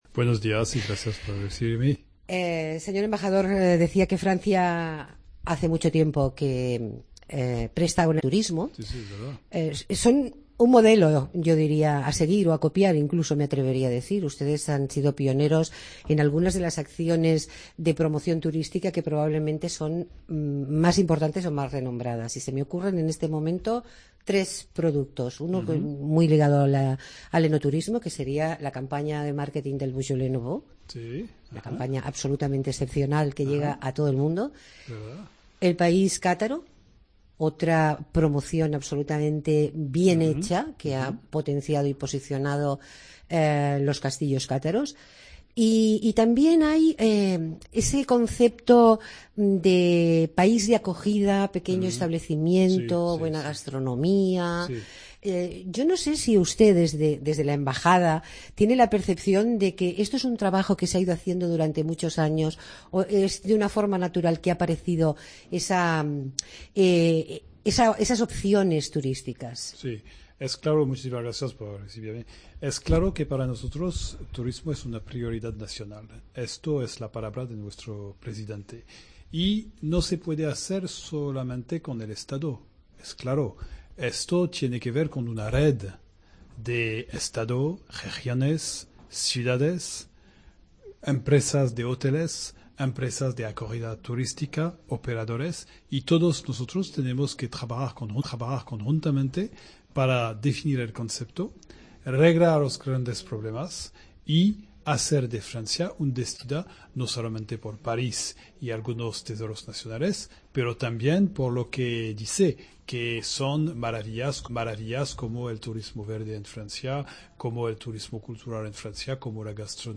Entrevista amn l'embaixador de França